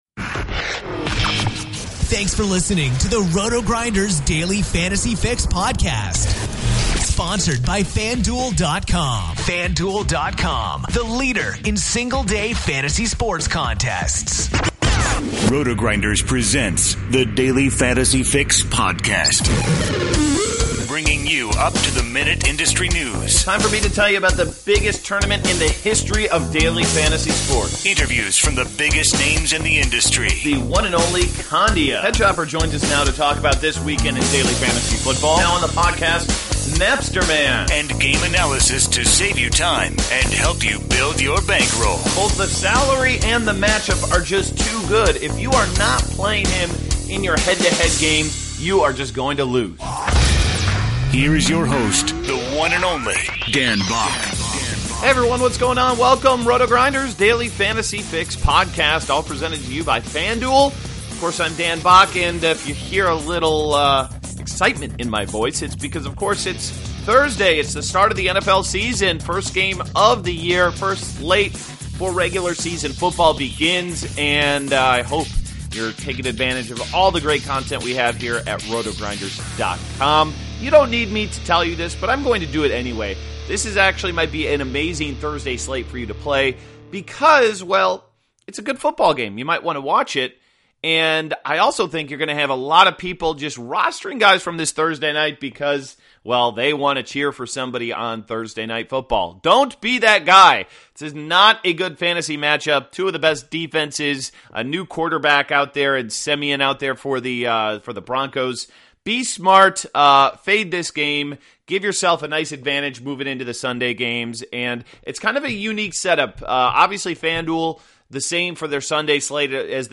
RotoGrinders Daily Fantasy Fix: Jason Robins Interview